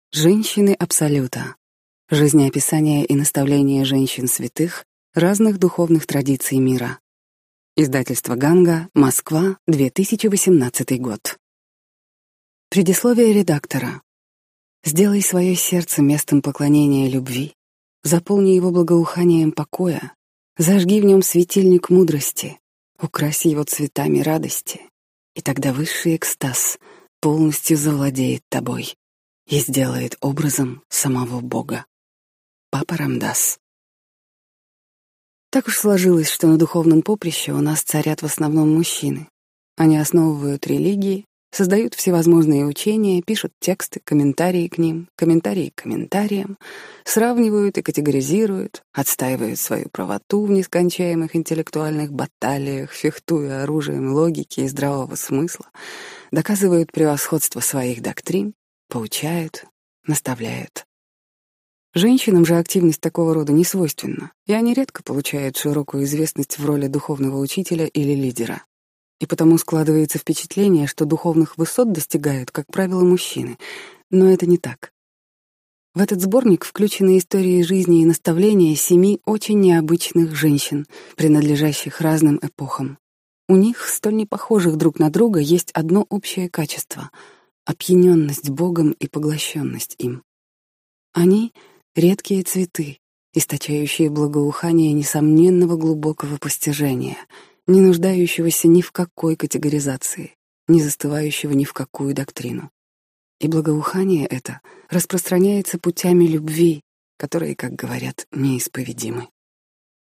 Аудиокнига Женщины Абсолюта | Библиотека аудиокниг